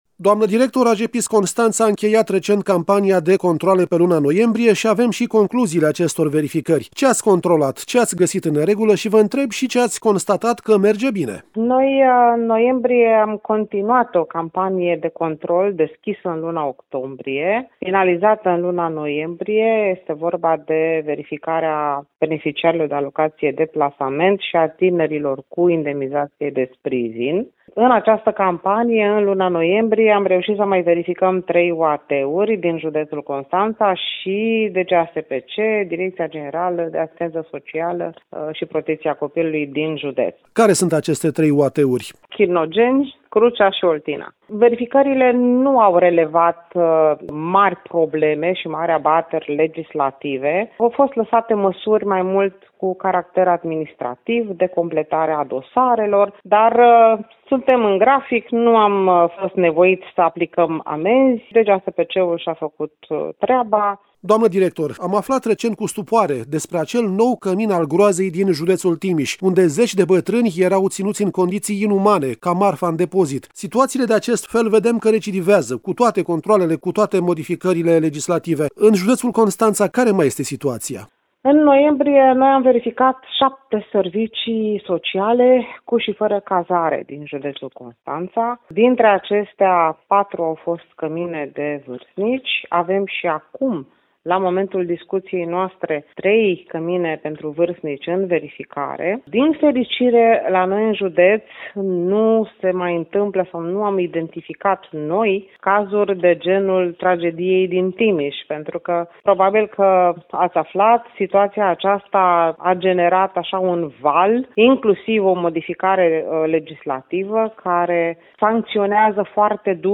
Amănunte, în interviul următor